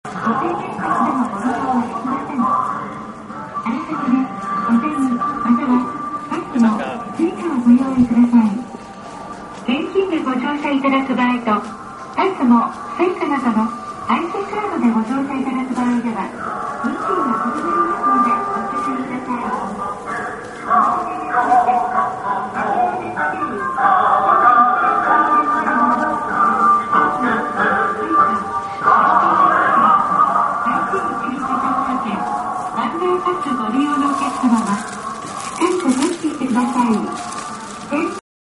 東京都小平市 2017年02月18日 西武新宿線花小金井駅前（バスの車外放送＋右翼宣伝カー）
hanakoganei_ekimae.mp3